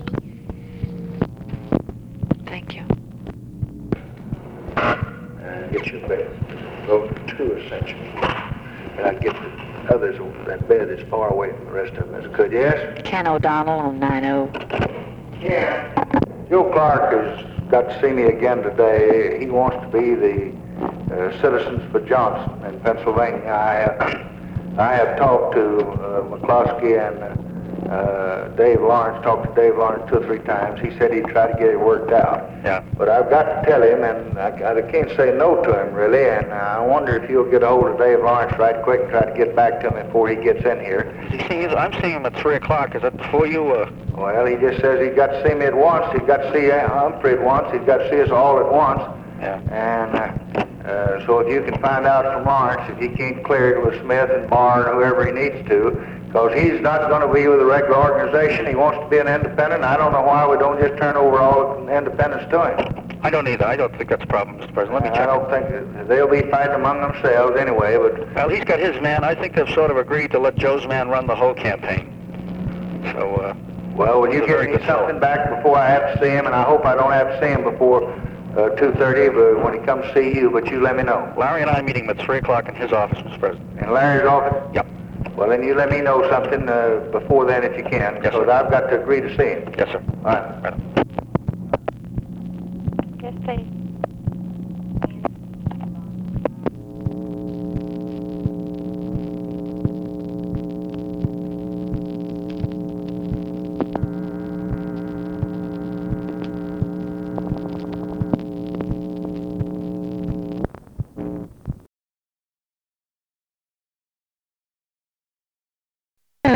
Conversation with KEN O'DONNELL and OFFICE CONVERSATION, September 1, 1964
Secret White House Tapes